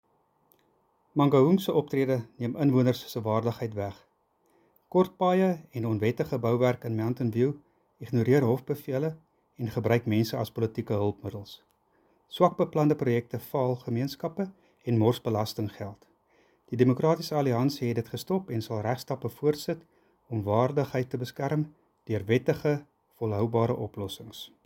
Afrikaans soundbite by Cllr Greg van Noord and